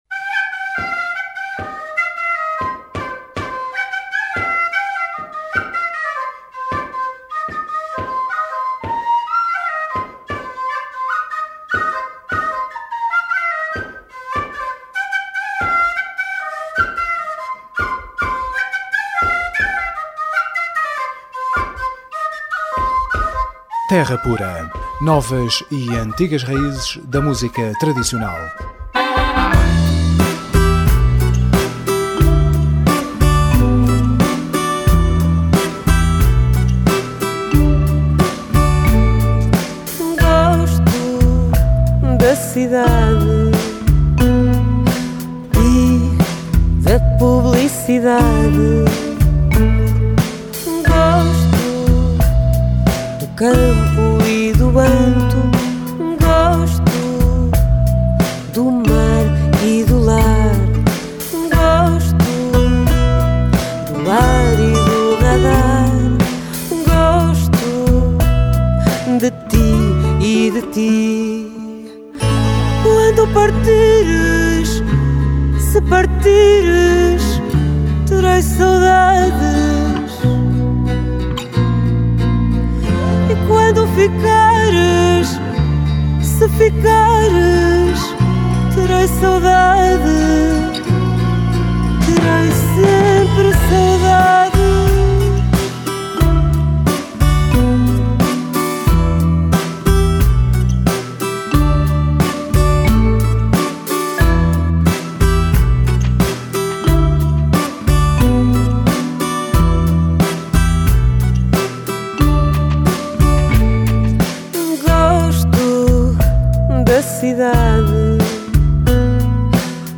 Terra Pura 29OUT12: Entrevista A Naifa – Crónicas da Terra